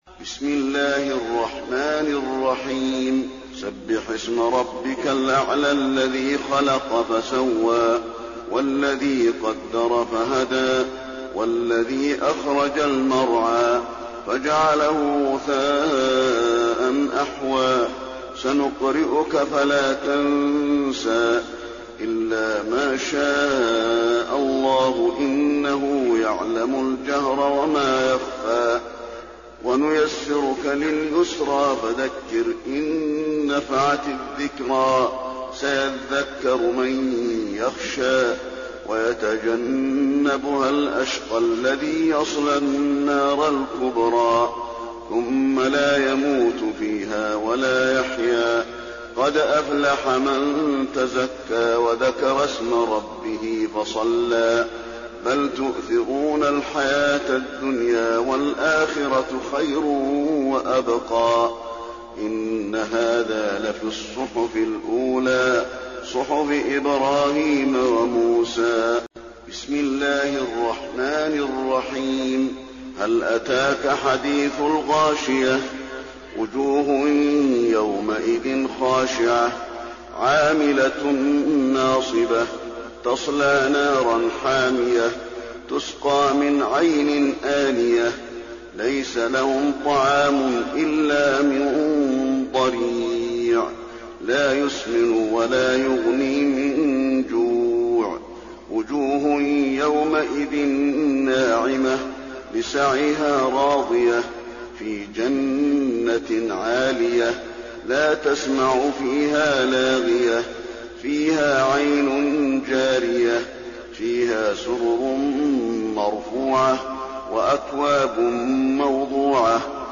تراويح ليلة 29 رمضان 1422هـ من سورة الأعلى الى الناس Taraweeh 29 st night Ramadan 1422H from Surah Al-A'laa to An-Naas > تراويح الحرم النبوي عام 1422 🕌 > التراويح - تلاوات الحرمين